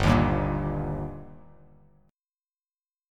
Gm#5 chord